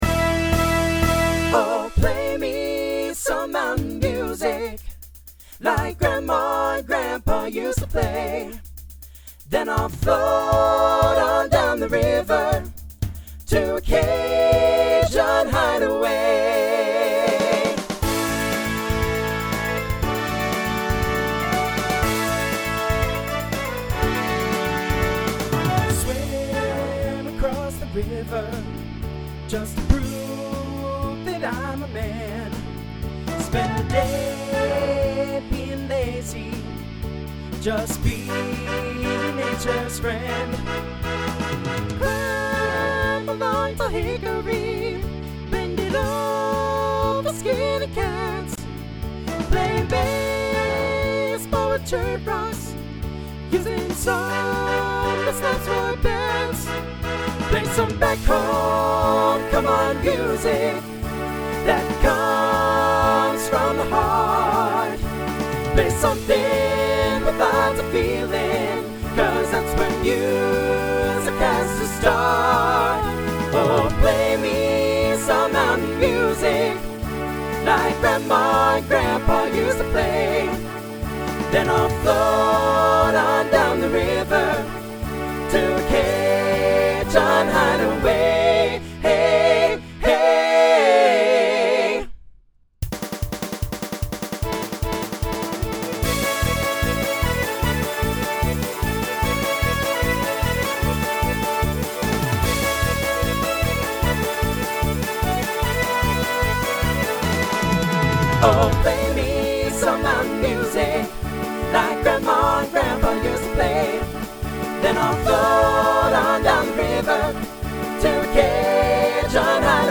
Voicing SATB Instrumental combo Genre Country